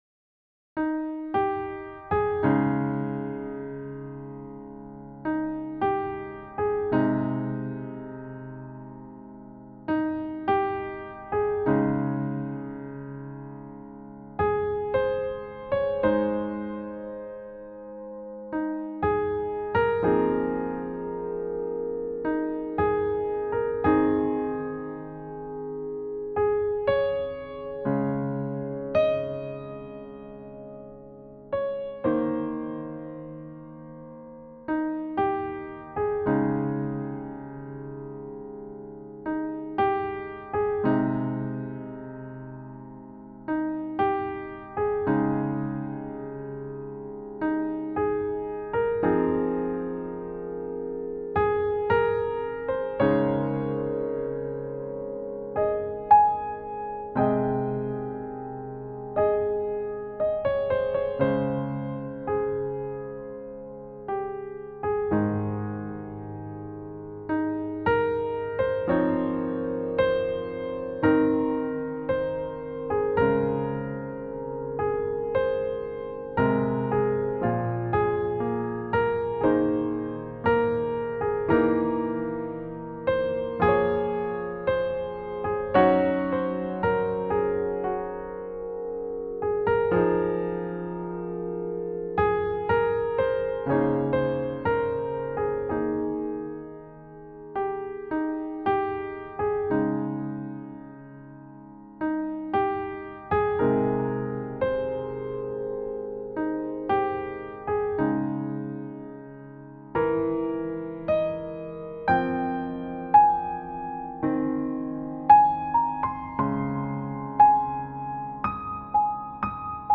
未分類 どこか懐かしい夏 バラード ピアノ 儚い 切ない 懐かしい 穏やか 青春 音楽日記 よかったらシェアしてね！